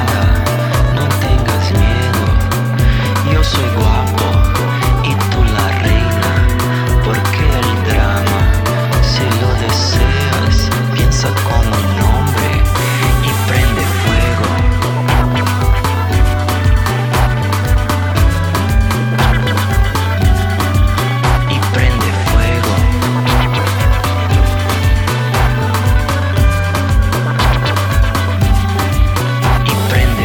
oniriques et pétillantes
• Chanteur :